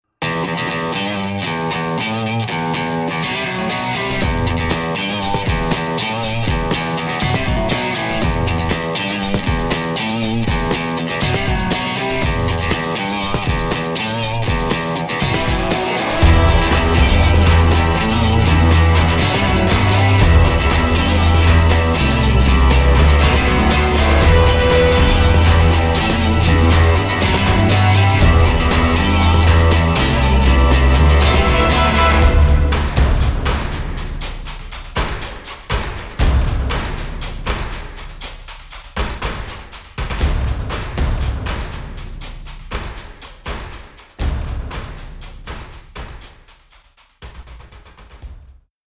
(orchestral/rock)